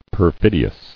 [per·fid·i·ous]